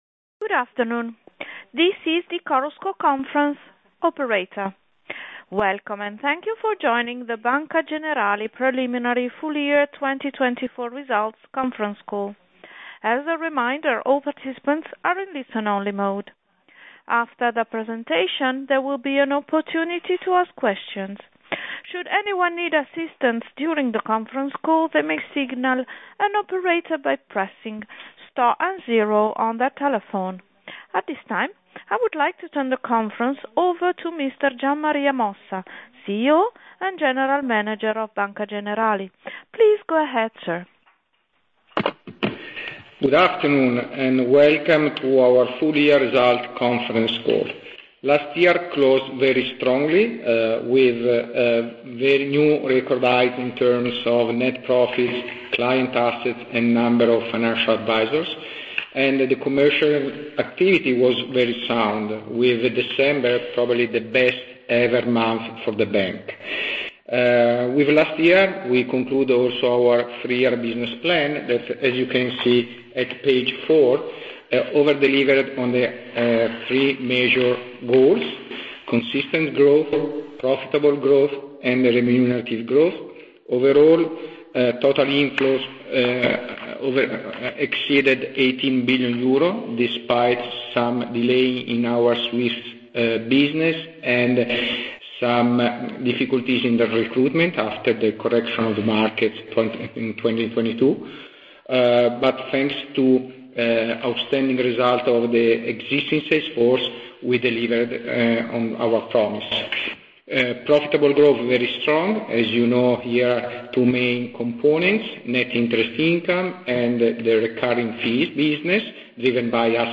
Risultati Preliminari 2025 - Audio Conf. Call